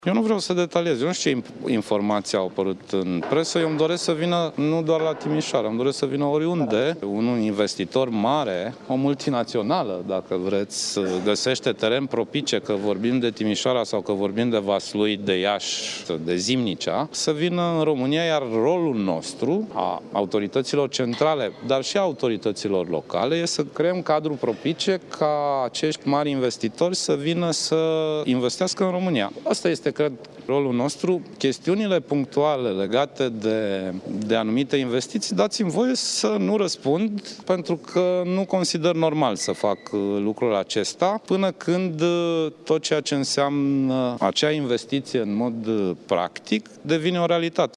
Grindeanu a subliniat, însă, că venirea unui mare investitor în România este importantă, indiferent de locul pe care l-ar alege pentru deschiderea unei companii: